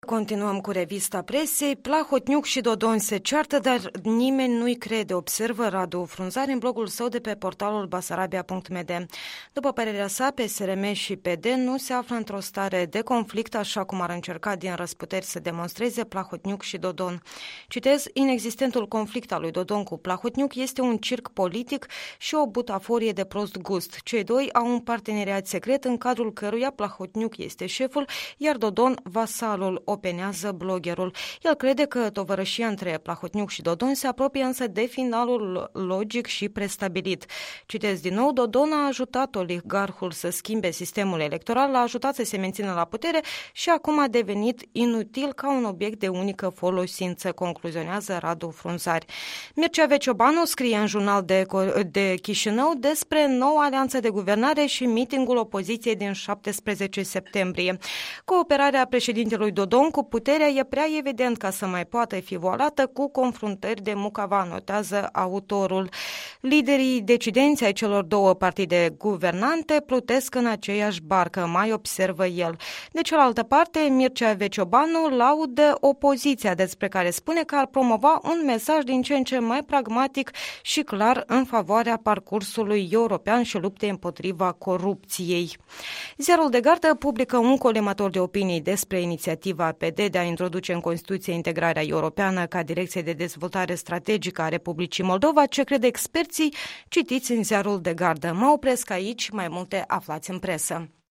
Revista presei despre inițiativa PD de a introduce în Constituție apropierea R.Moldova de UE